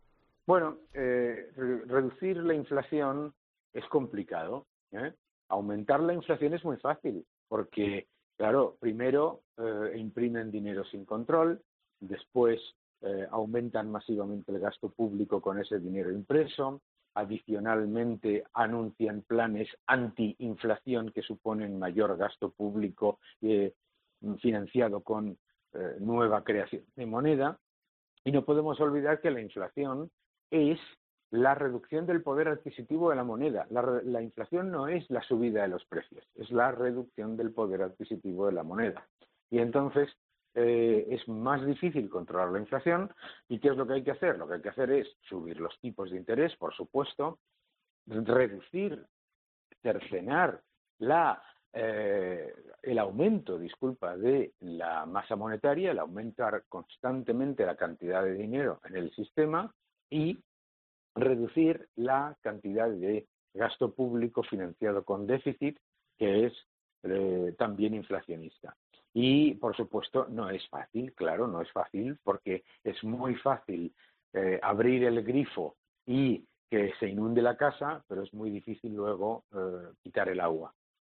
El economista Daniel Lacalle analiza cómo afecta a nuestro bolsillo la subida de la inflación subyacente